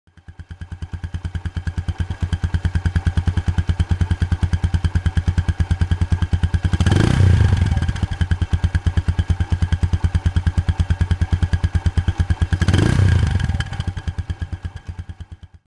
Motor124 cc, 11.26 HP, 10 Nm @7,000 RPM
Escucha su motor [